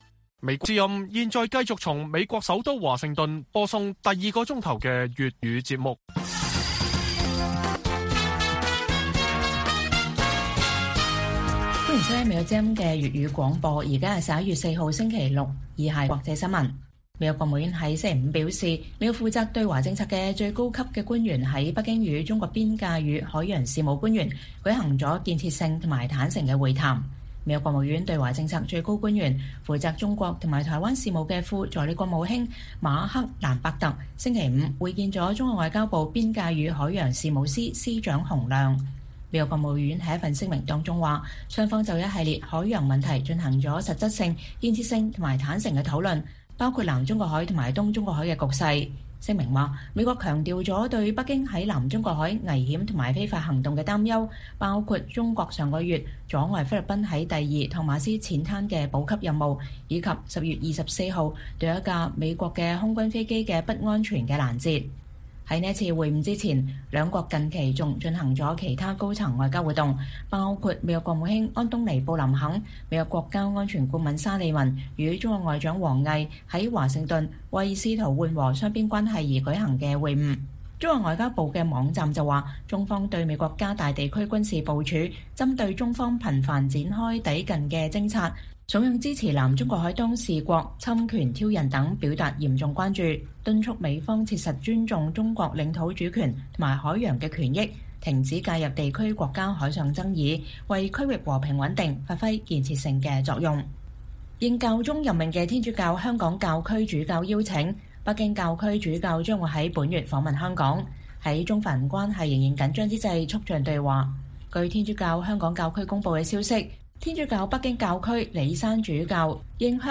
粵語新聞 晚上10-11點：美中海洋事務磋商各自表達涉海議題關注